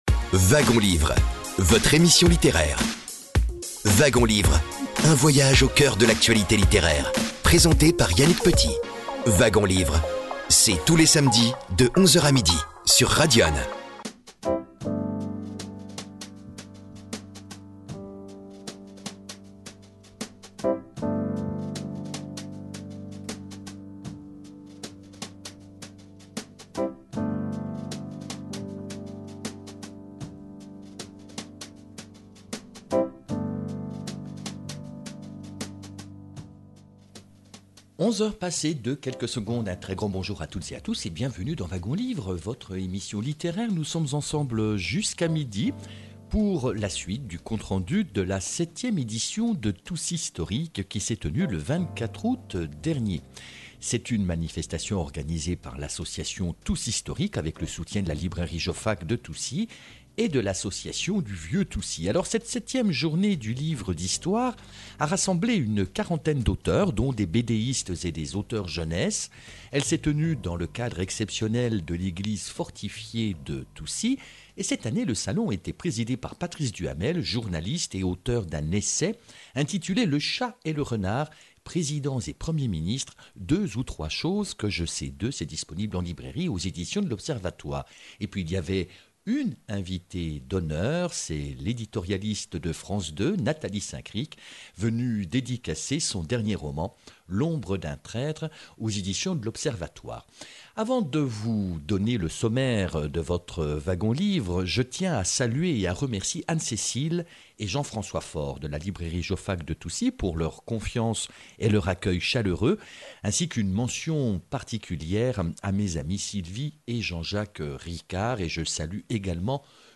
Organisée par l’Association Toucy’Storic, avec le soutien de la librairie Jofac de Toucy et de l’Association du Vieux Toucy, la 7e Journée du Livre d’Histoire a rassemblé une quarantaine d’auteurs dont des bédéistes et des auteurs jeunesse, samedi 24 août 2024. Elle s’est tenue dans le cadre exceptionnel de l’église (fortifiée) Saint-Pierre, à Toucy (89).